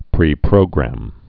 (prē-prōgrăm, -grəm)